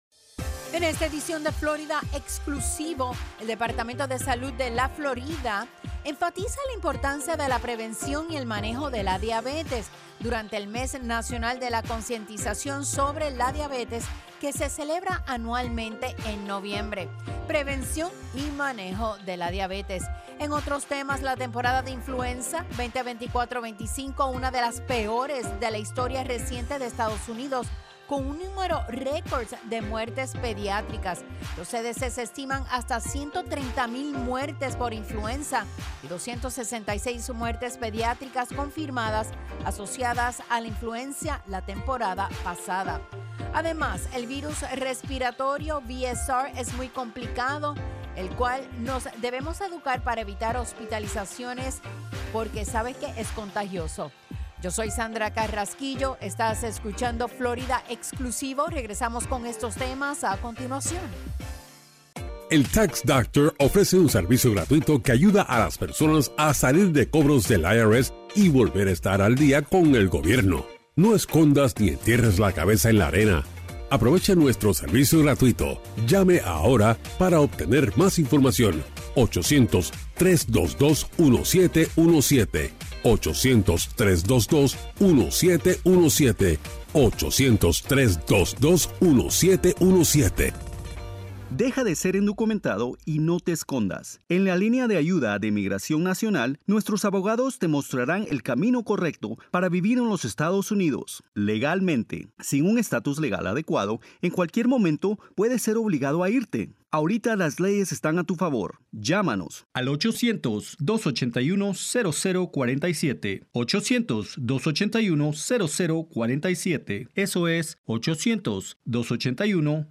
FNN's Florida Exclusivo is a weekly, one-hour news and public affairs program that focuses on news and issues of the Latino community.